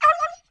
client / bin / pack / Sound / sound / monster / stray_dog / fall_1.wav
fall_1.wav